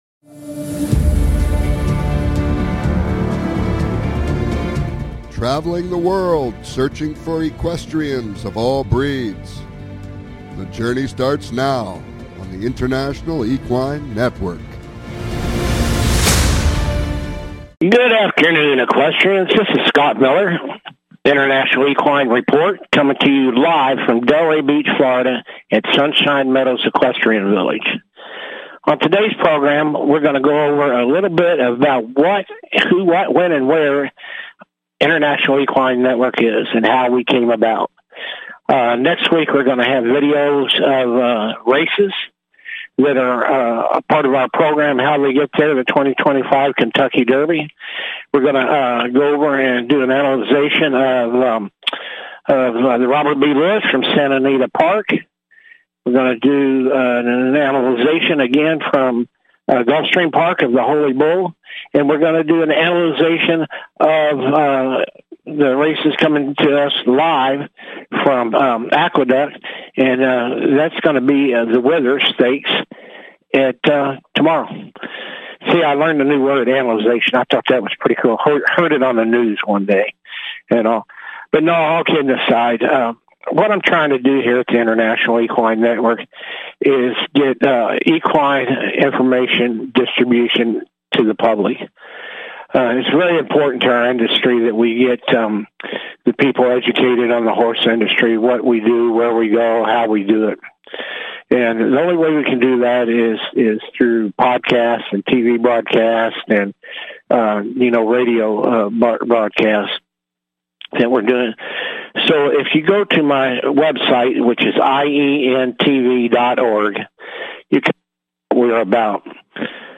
International Equine Network Talk Show
Calls-ins are encouraged!